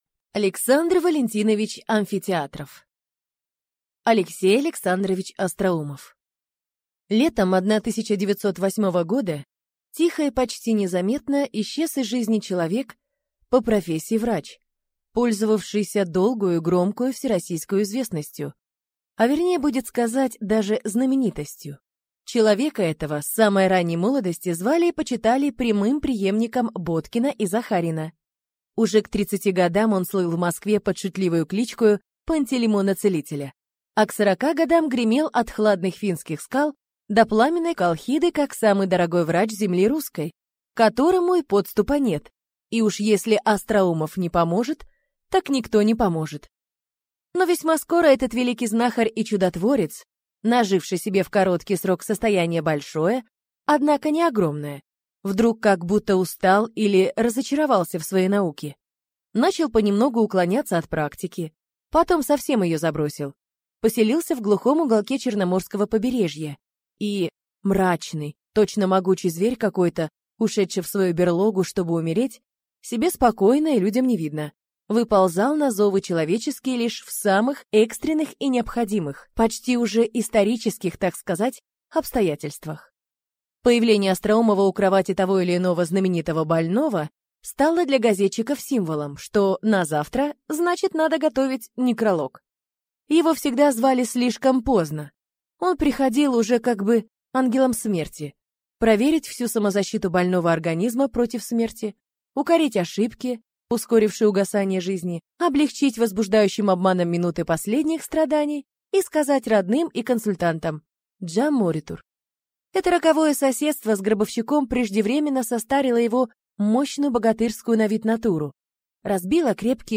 Аудиокнига Алексей Александрович Остроумов | Библиотека аудиокниг